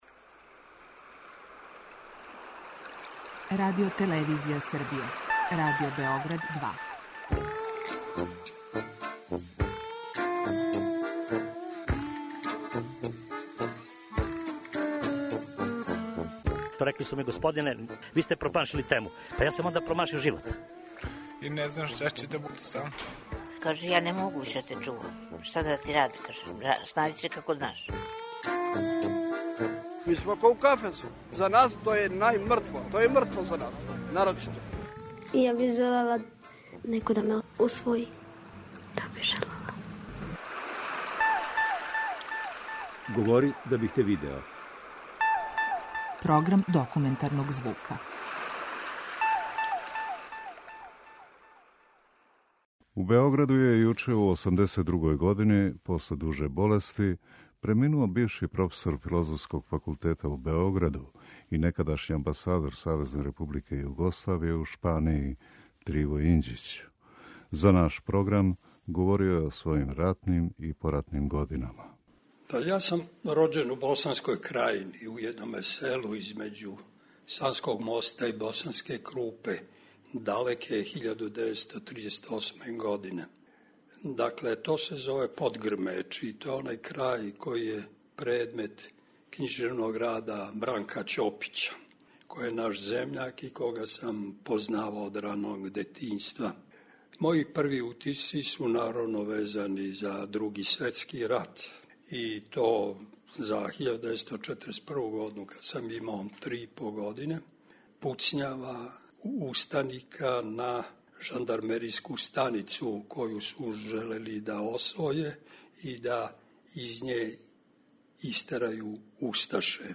Документарни програм
Слушаћемо репортажу у којој је Триво Инђић говорио о својим ратним и поратним годинама.